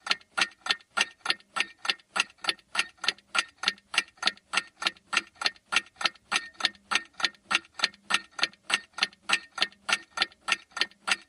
TicTac.mp3